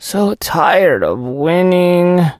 sandy_lead_vo_03.ogg